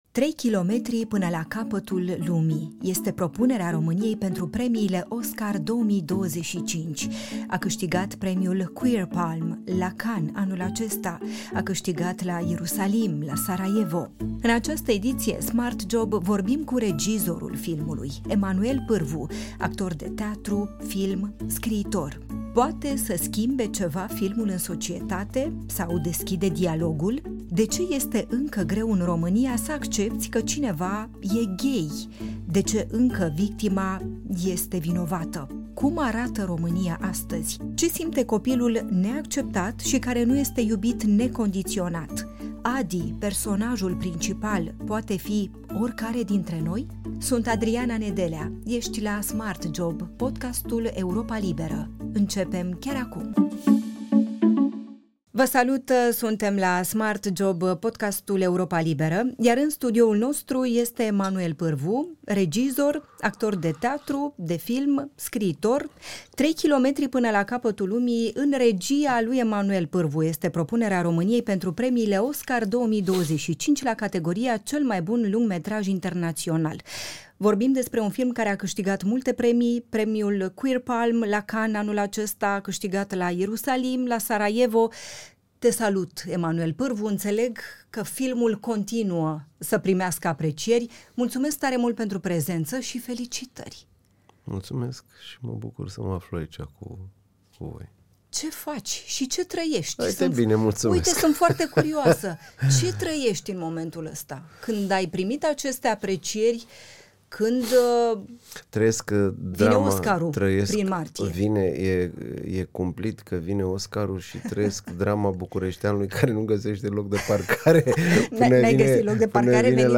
Regizorul Emanuel Pârvu vorbește la SmartJob despre discriminare, despre iubirea necondiționată părinte-copil, despre gura lumii și ce riști dacă te iei după ea.